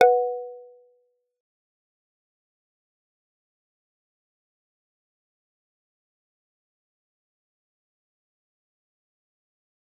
G_Kalimba-B4-f.wav